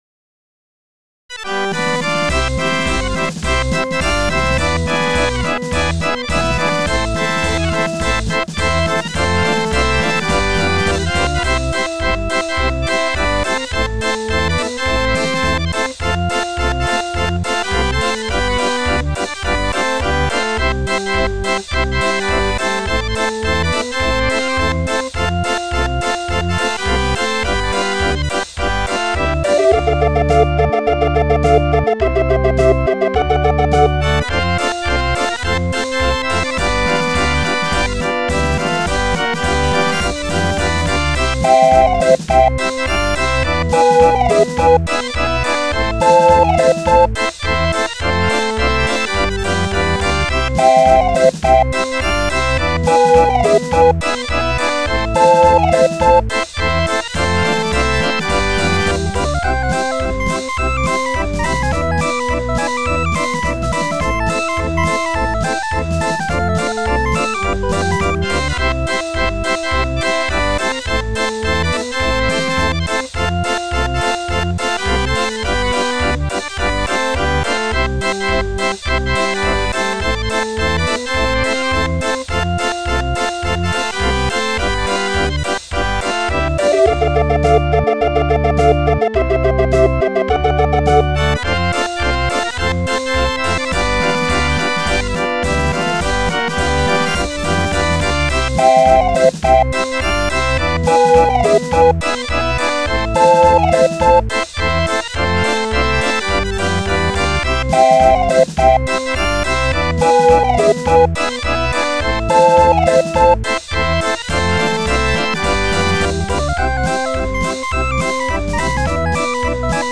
Music rolls, music books and accessoires for barrel organs.